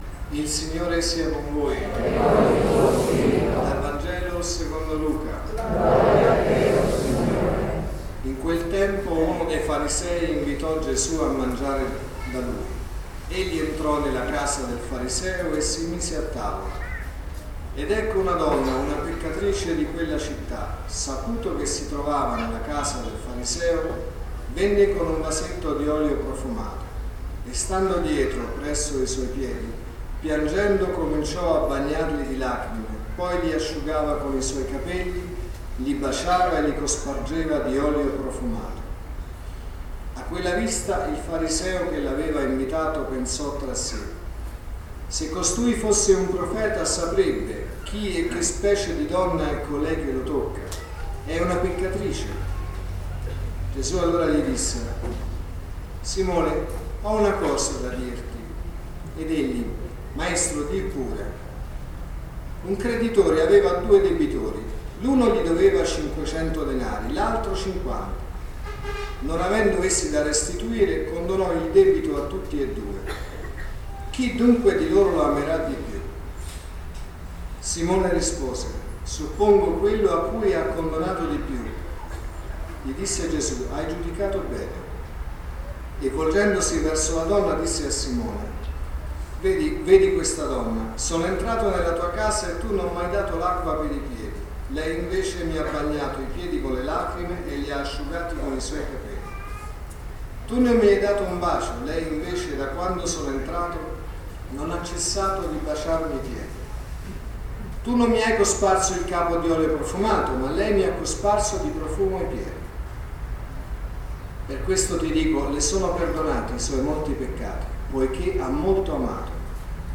peccatrice.mp3